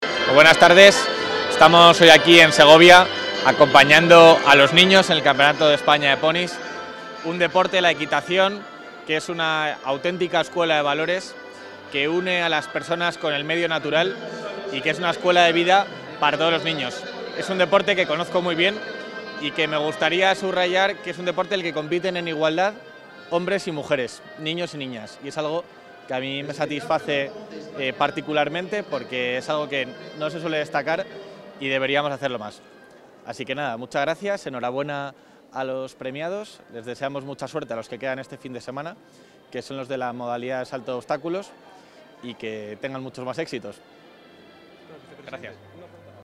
Declaraciones del vicepresidente.
El vicepresidente de la Junta de Castilla y León, Juan García-Gallardo, ha acudido hoy en Segovia a la XXXIV edición del Campeonato de España de Equitación con Ponis y Copa de España.